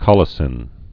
(kŏlĭ-sĭn, kōlĭ-)